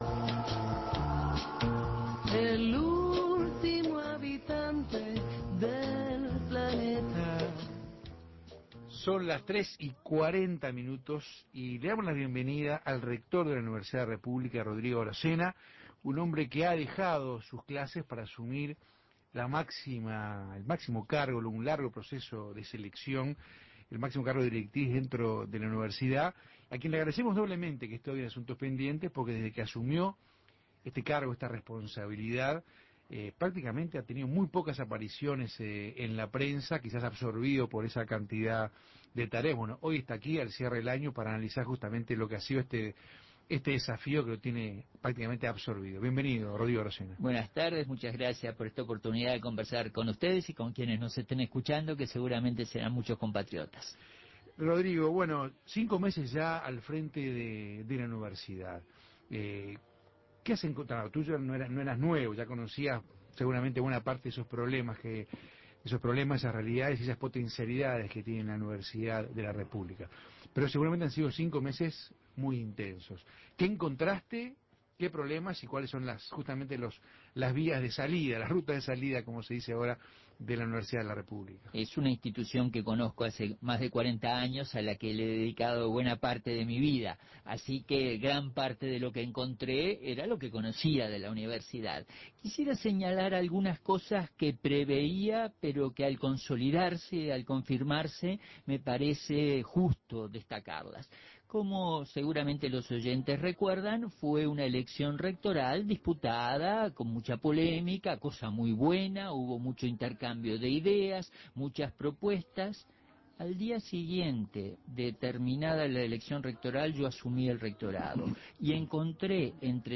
El rector de la Universidad de la República, Rodrigo Arocena, es un hombre que dejó sus clases para asumir el máximo cargo dentro de la Universidad. Luego de cinco meses de gestión, fue entrevistado en Asuntos Pendientes y explicó los problemas y posibilidades que tiene la enseñanza superior a nivel nacional.